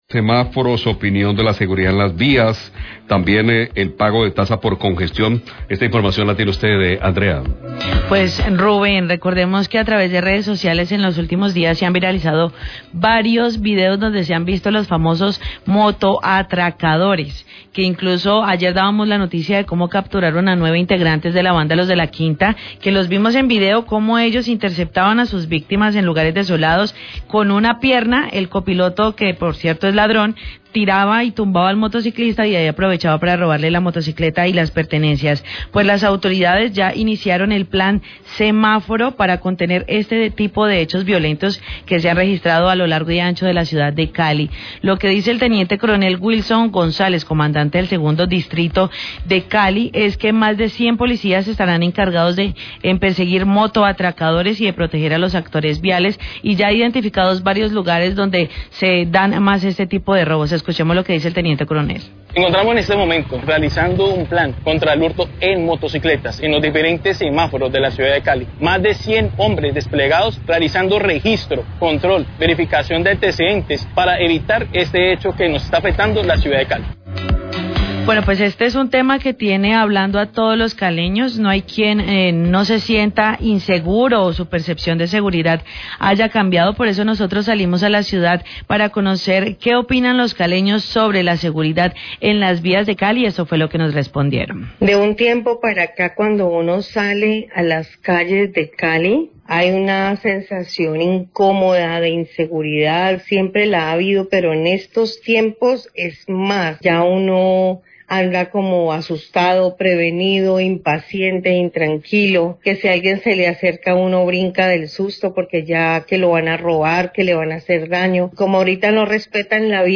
Radio
Testimonios de los caleños muestran que se sienten inseguros en las vías y en los semáforos de la ciudad.